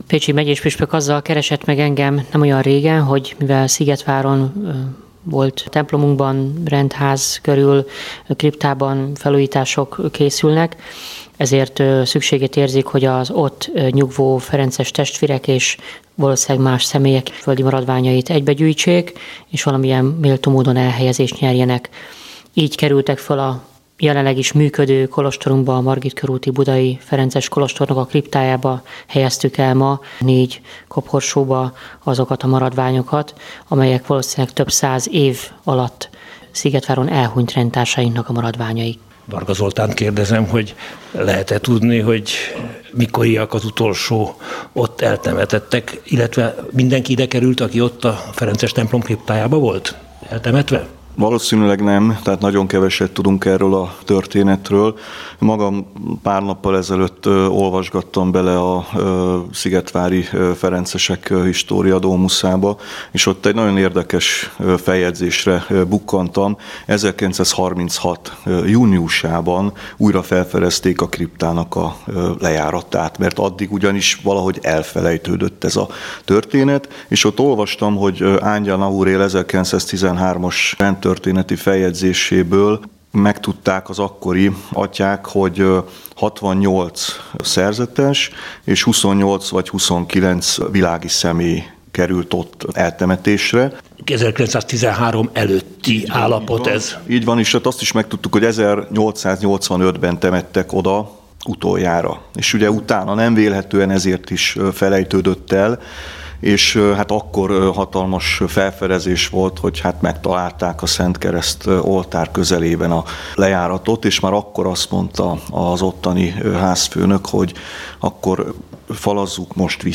A szigetvári ferences templomot és rendházat Varga Zoltán alpolgármester mutatta be egy korábbi műsorban, amely 2016. március 13-án hangzott el a Katolikus Rádióban.